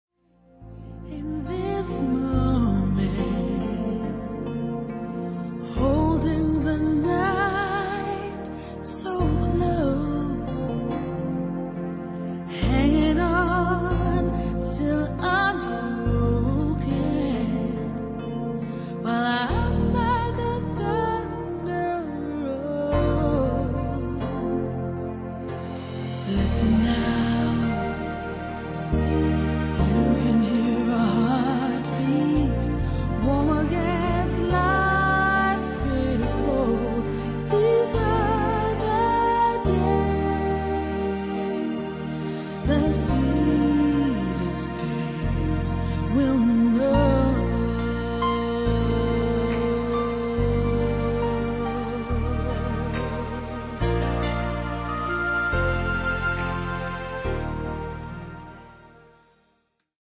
NOTE: Vocal Tracks 1 Thru 8